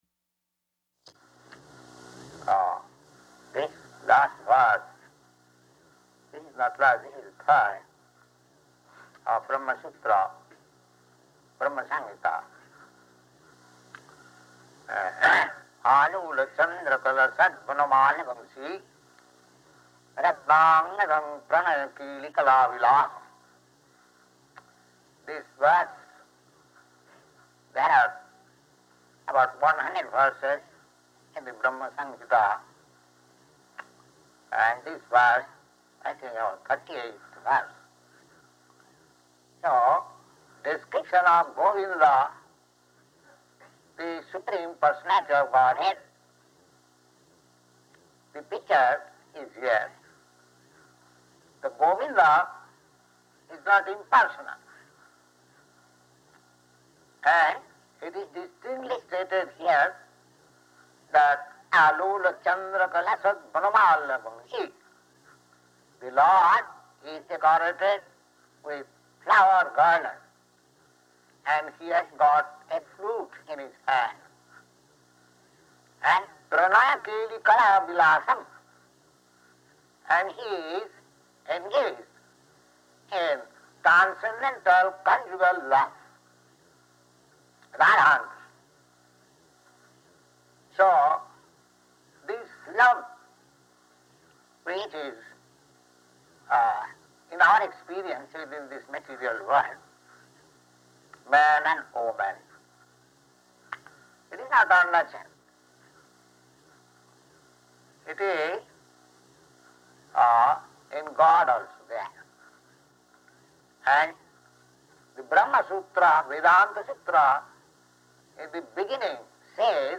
-- Type: Lectures and Addresses Dated: July 22nd 1968 Location: Montreal Audio file: 680722WL-MONTREAL.mp3 [Poor audio] Prabhupāda